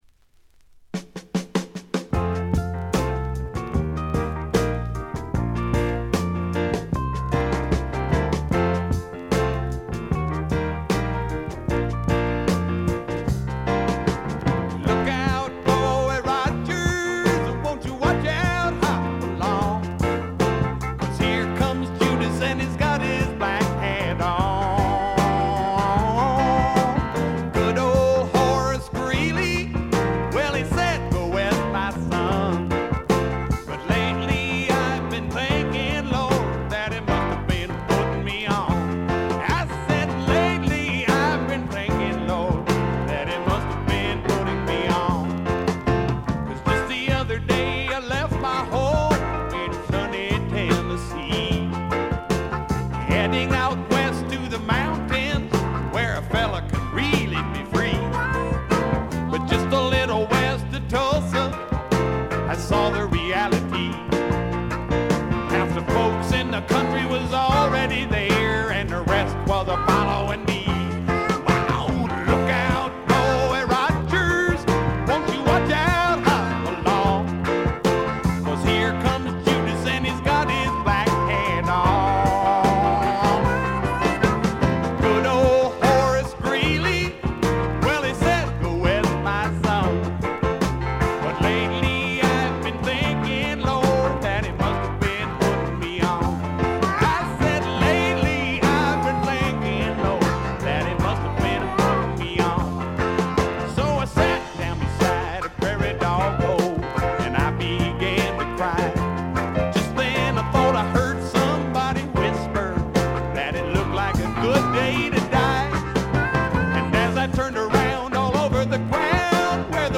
バックグラウンドノイズ、チリプチ多め（特にB1あたり）。散発的なプツ音少し。
スワンプ系シンガーソングライター作品の基本定番。
試聴曲は現品からの取り込み音源です。
Vocals, Acoustic Guitar